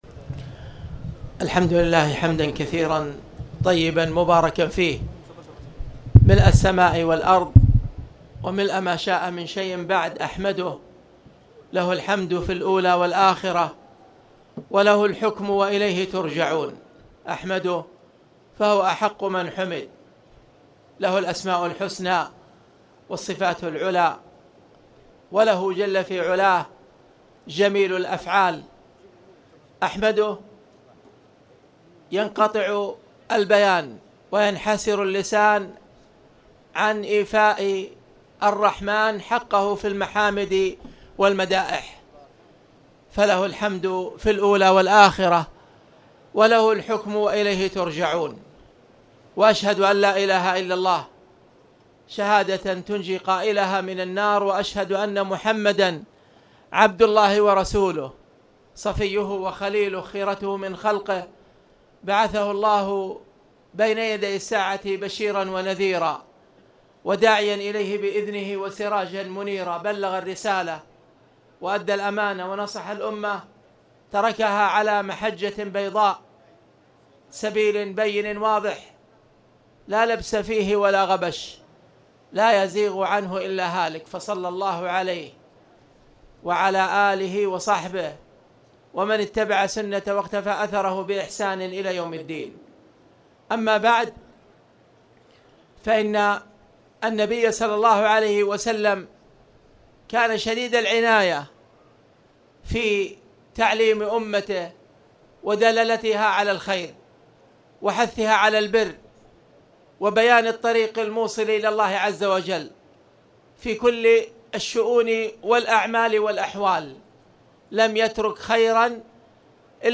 تاريخ النشر ٥ جمادى الآخرة ١٤٣٨ هـ المكان: المسجد الحرام الشيخ: خالد بن عبدالله المصلح خالد بن عبدالله المصلح كتاب الصلاة